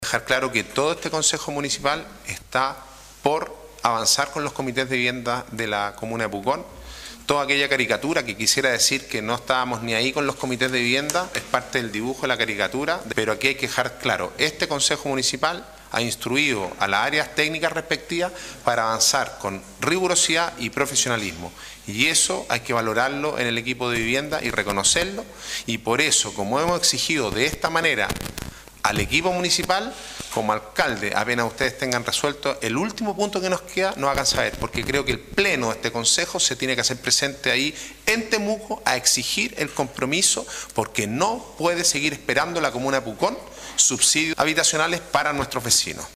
En el último Concejo comunal se conocieron los enormes avances que ha tenido en torno al tema una zona que también espera el aporte, con recursos, del Ministerio de Vivienda y Urbanismo (MINVU).